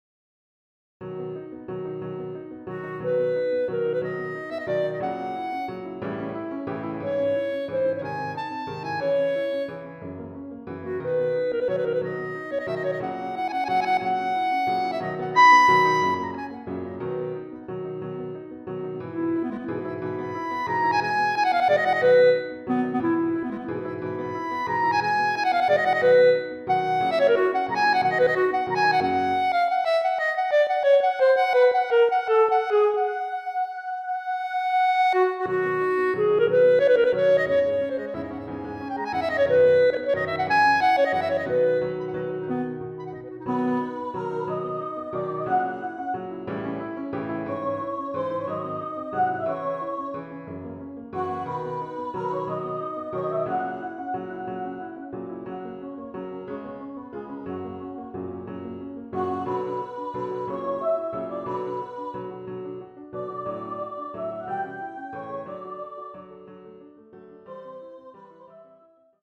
Voicing: Mixed Ensemble